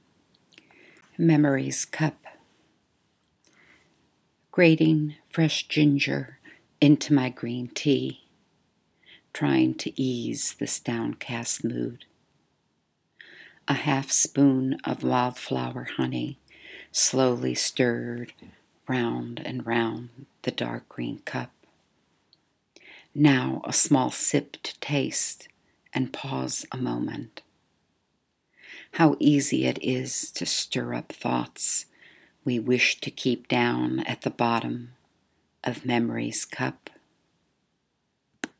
If you would like to hear me read this poem, please click on the audio link below.